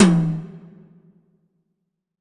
WTOM 4.wav